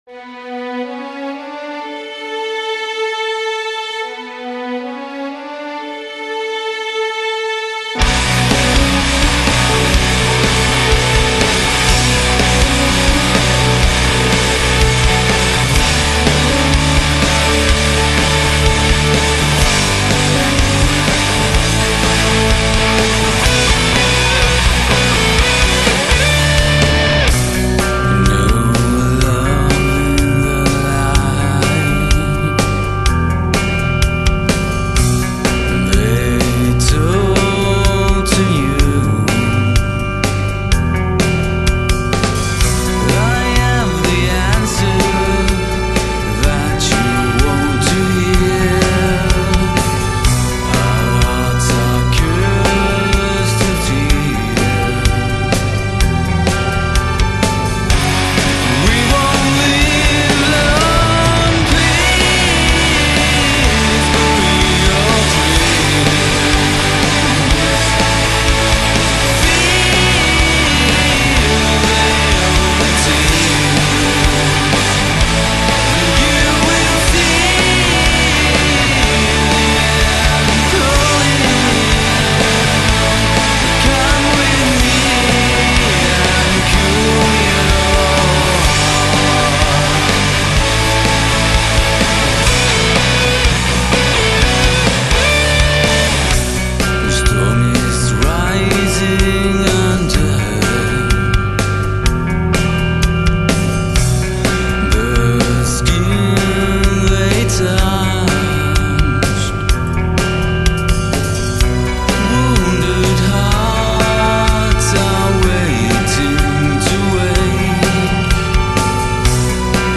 Genre: rock gothique